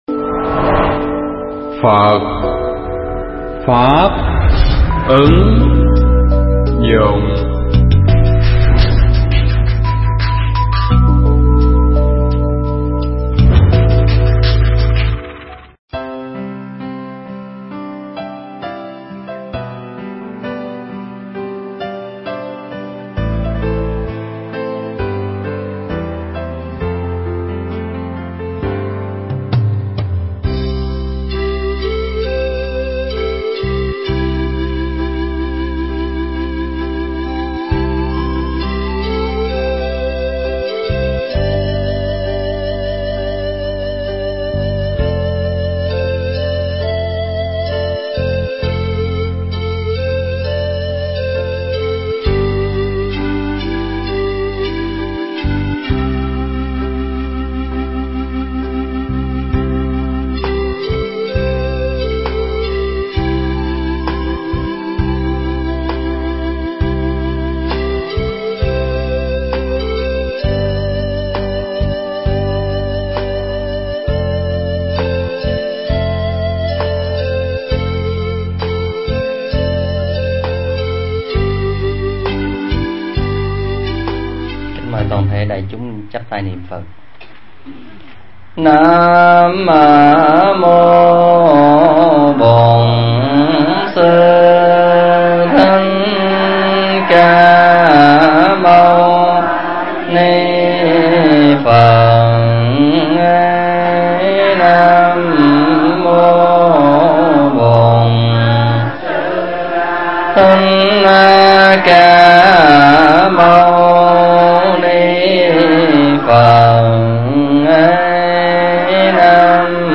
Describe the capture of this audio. giảng tại Niệm Phật Đường Phổ Quang (Hoa Kỳ)